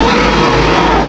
pokeemerald / sound / direct_sound_samples / cries / rhyperior.aif